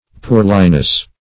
Poorliness \Poor"li*ness\, n.